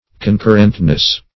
Search Result for " concurrentness" : The Collaborative International Dictionary of English v.0.48: Concurrentness \Con*cur"rent*ness\, n. The state or quality of being concurrent; concurrence.